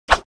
swing_1.wav